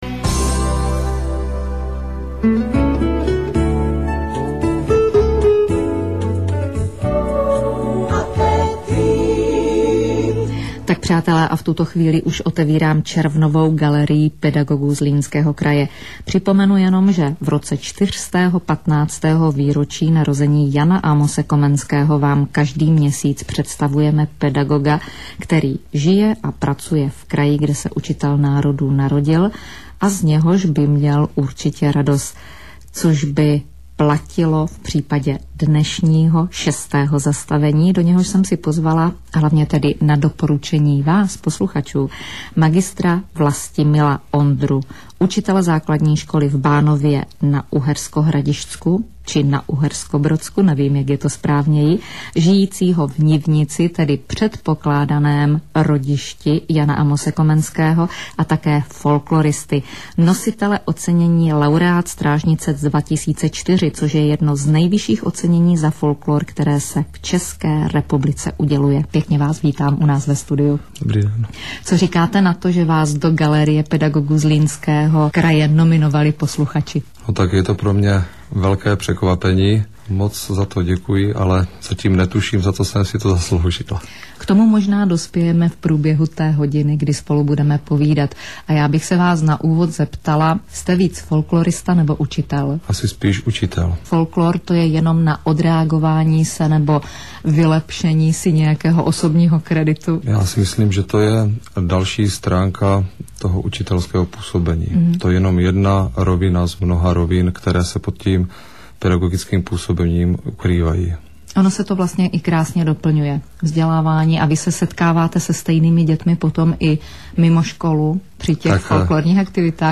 �ervna 2007, vys�l�no na stanici �esk� rozhlas Brno 19. �ervna 2007 (nahr�vka je z ve�ern� zkr�cen� repr�zy - a je�t� nen� �pln�, ke konci ob�as vypadl net...)